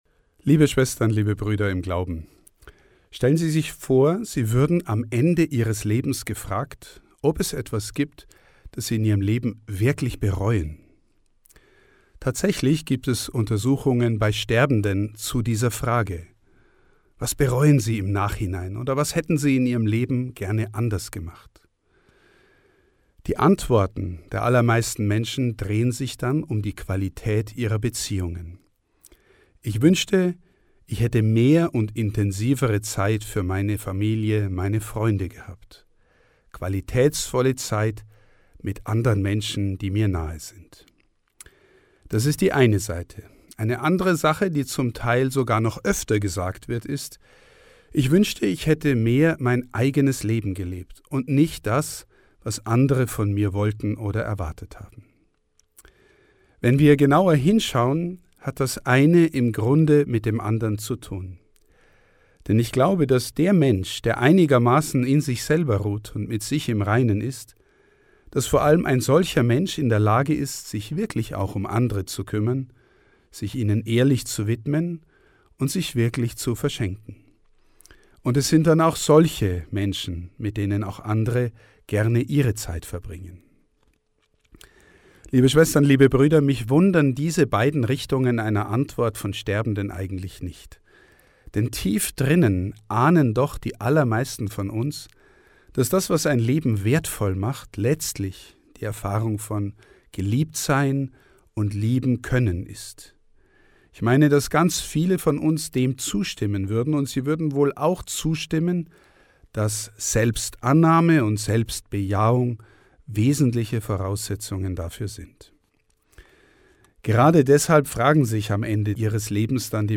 Hirtenwort zur Fastenzeit 2025